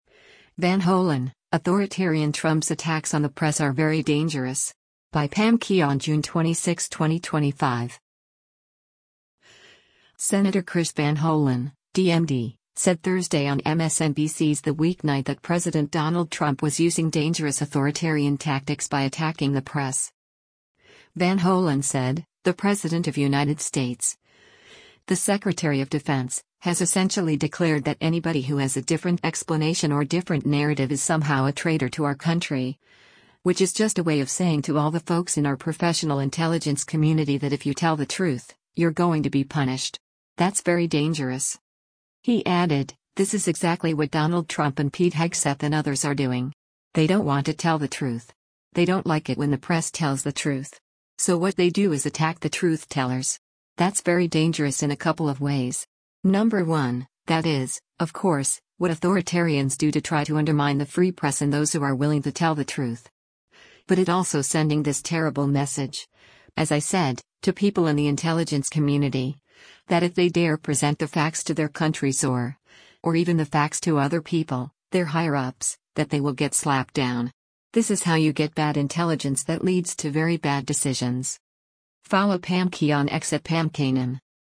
Senator Chris Van Hollen (D-MD) said Thursday on MSNBC’s “The Weeknight” that President Donald Trump was using “dangerous” authoritarian tactics by attacking the press.